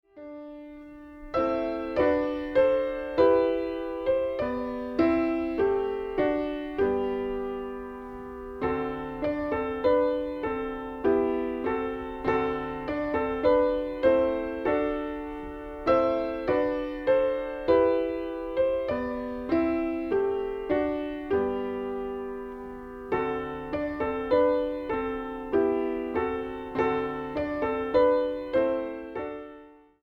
Piano/Organ Ensembles Piano Duets
Piano Duet